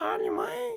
Audio / SFX / Characters / Voices / PigChef / PigChef_09.wav